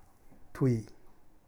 スピーカとぅい〈干支〉（多良間方言）
tu ティー・ユー